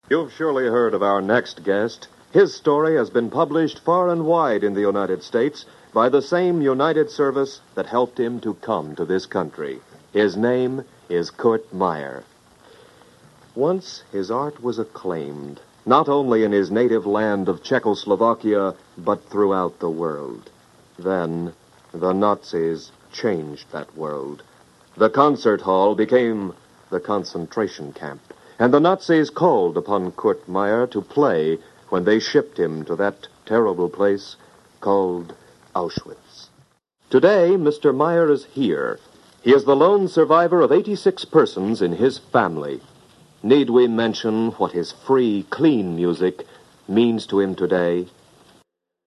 On April 6, 1947, the United Service for New Americans sponsored a special Passover celebration to be aired on the radio. The broadcast, Out of the Wilderness, featured five Jewish survivors of the Holocaust who gave musical performances on air.